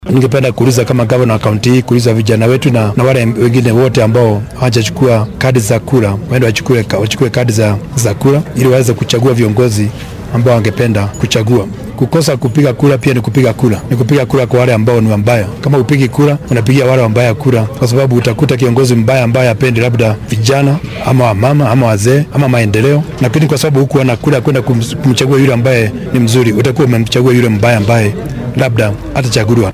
Guddoomiyaha ismaamulka Nyandarua ee gobolka bartamaha dalka Francis Kimemia ayaa dadweynaha ugu baaqay inay isku diiwaangeliyaan ka qayb galka doorashada